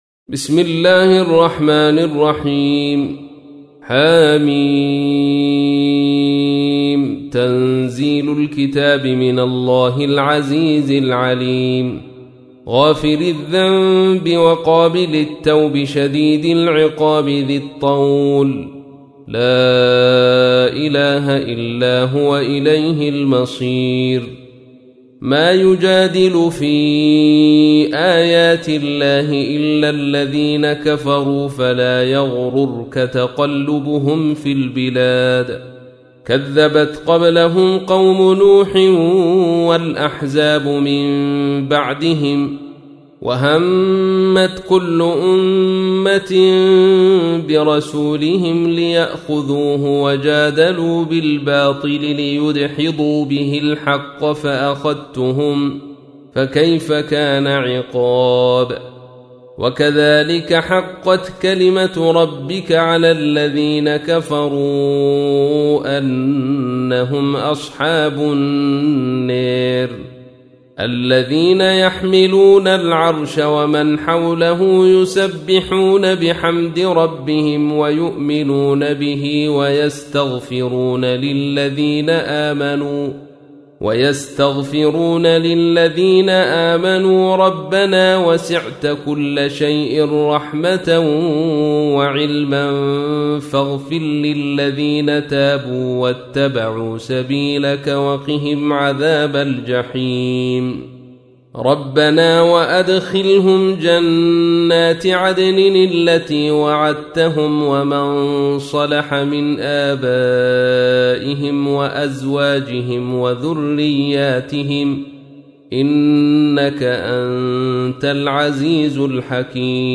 تحميل : 40. سورة غافر / القارئ عبد الرشيد صوفي / القرآن الكريم / موقع يا حسين